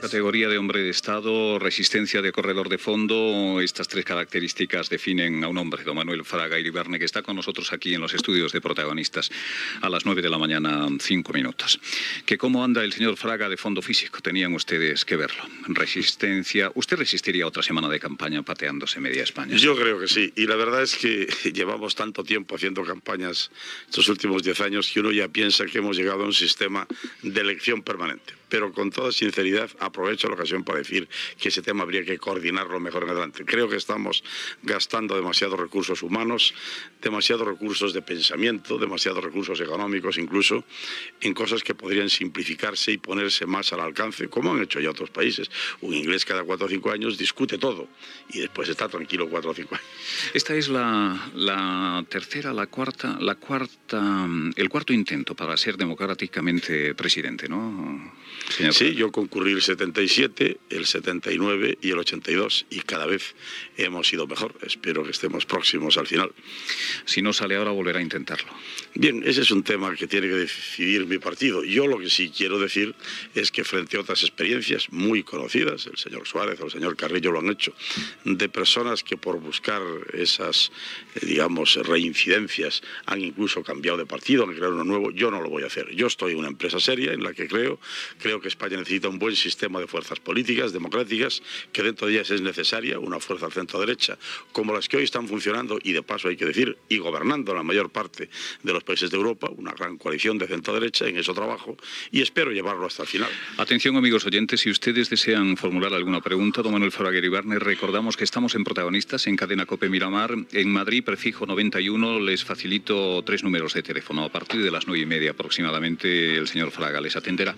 Fragment d'una entrevista al líder d'Alianza Popular, Manuel Fraga Iribarne, candidat a les eleccions generals de 1986.
Info-entreteniment